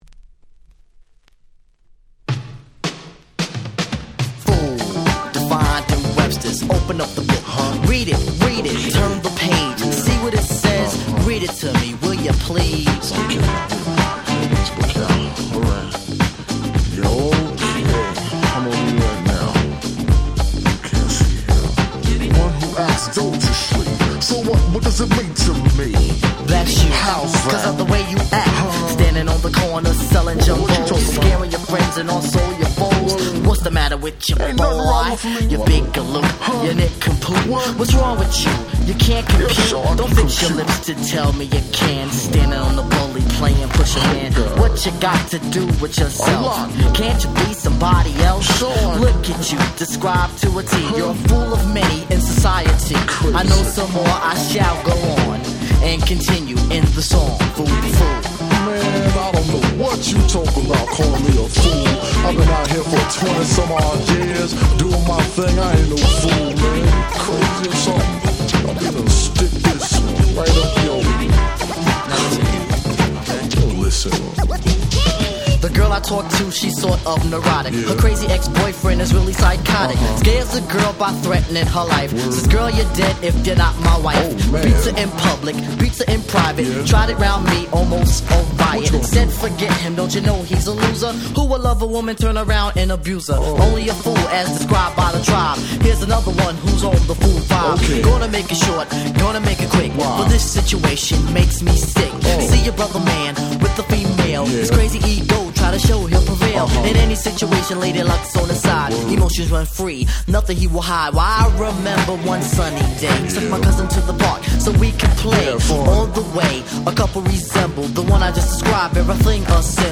89' Smash Hit Hip Hop !!
New School Classics !!
Old School味あふれる初期の人気曲！！